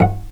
vc_pz-F#5-pp.AIF